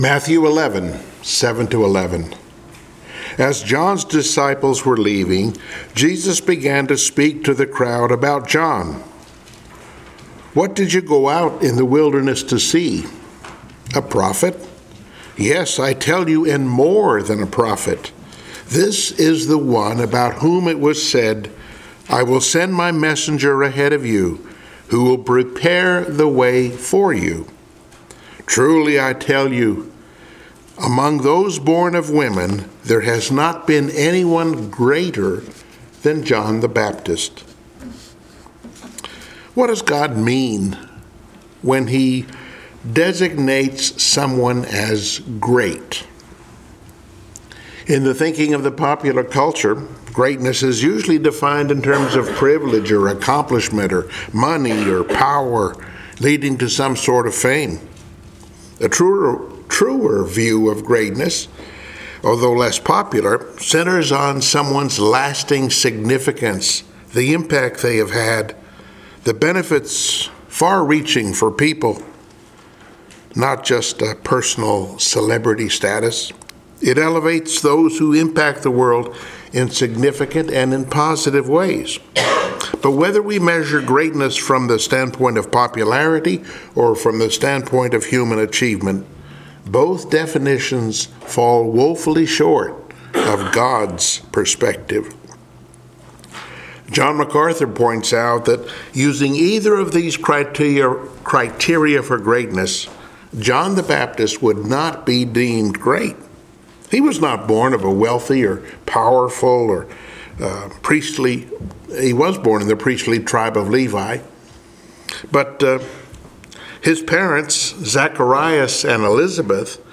Passage: Mathew 11:7-11 Service Type: Sunday Morning Worship